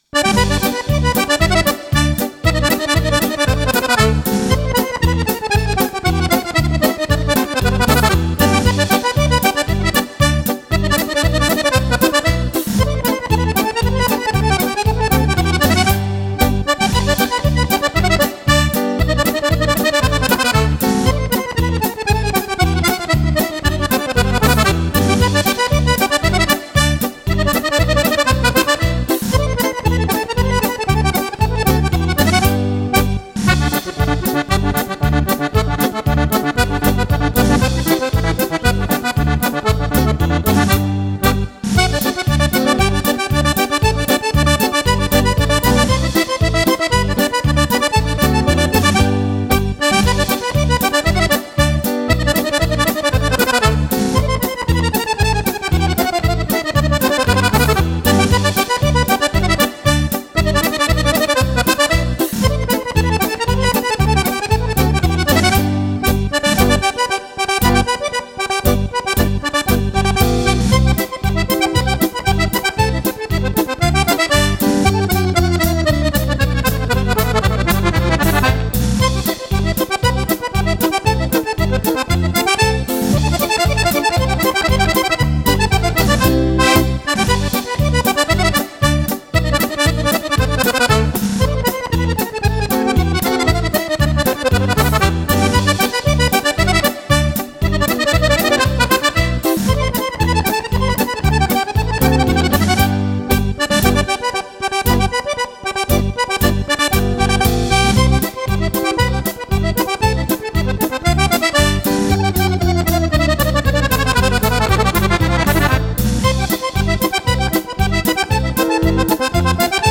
Polka
ballabili per sax e orchestra stile Romagnolo.